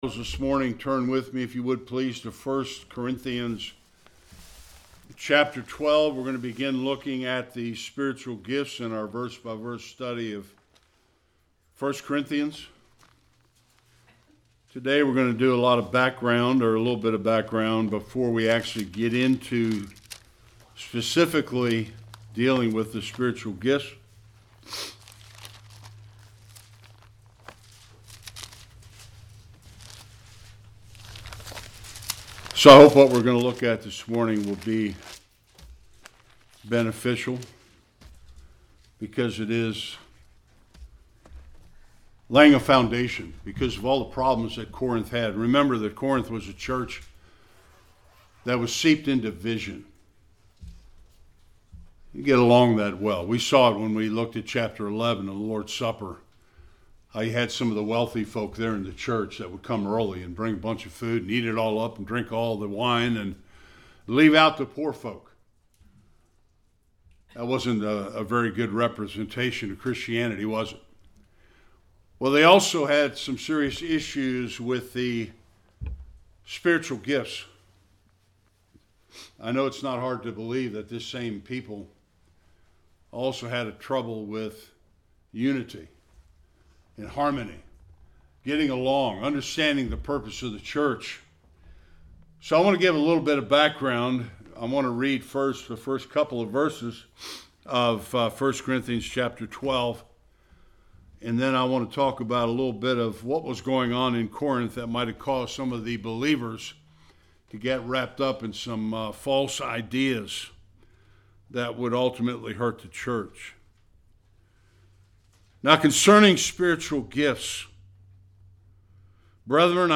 1-3 Service Type: Sunday Worship The introduction to the Spiritual gifts.